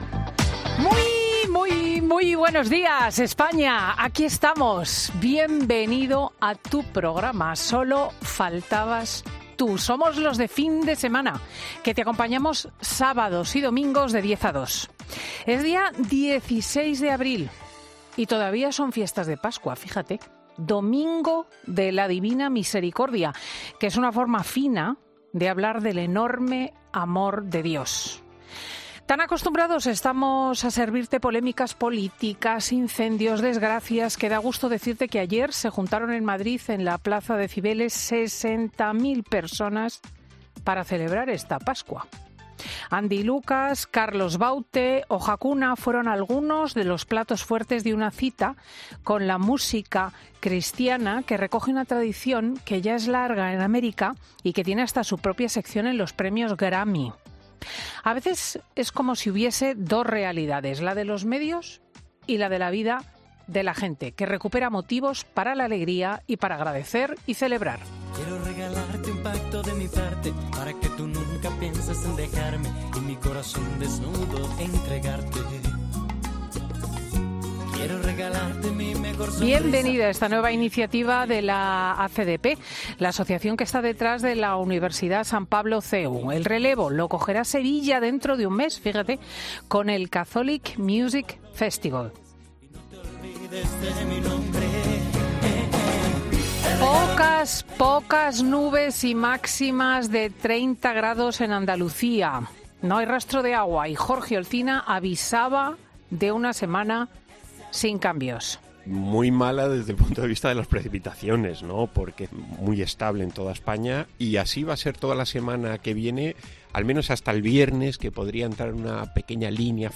Escucha el monólogo de Cristina López Schlichting de este 16 de abril de 2023